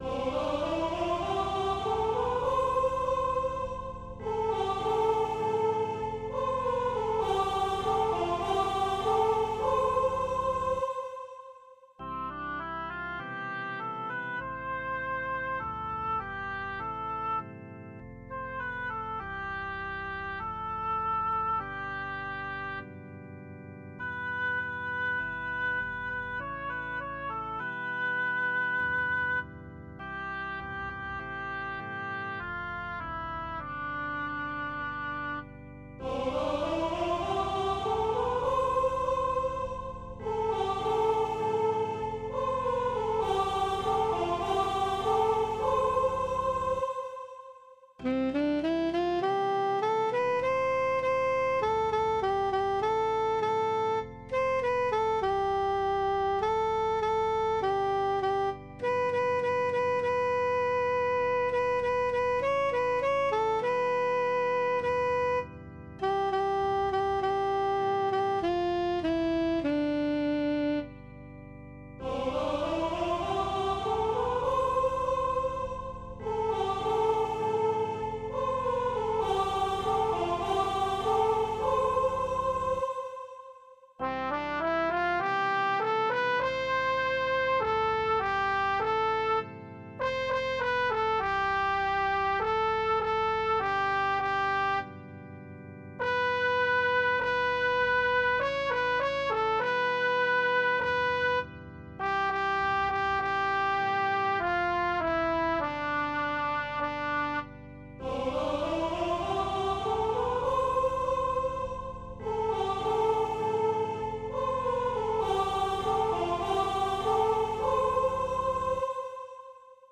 Filage audio synthétique (sans paroles) avec antienne ordinaire